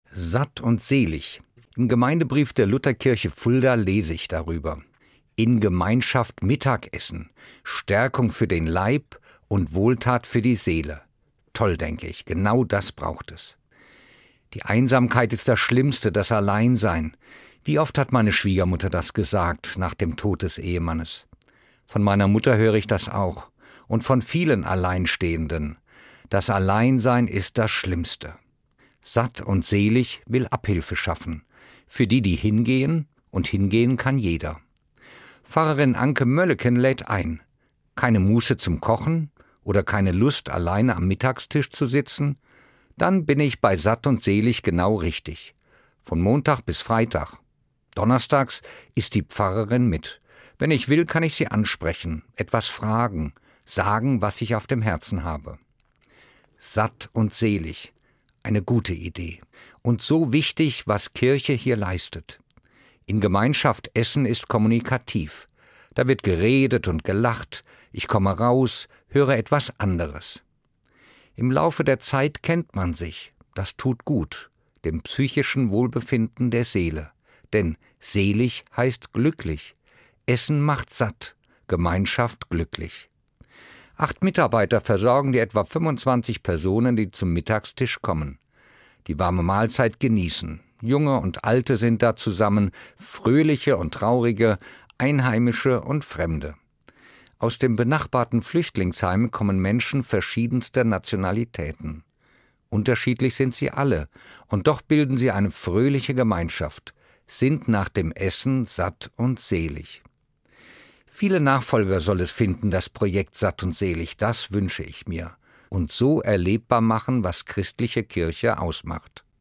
Evangelischer Pfarrer, Fulda